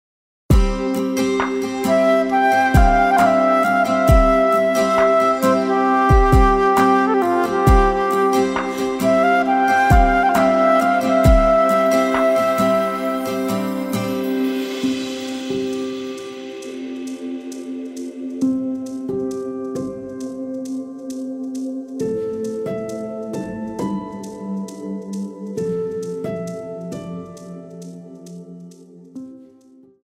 Wersja instrumentalna do piosenki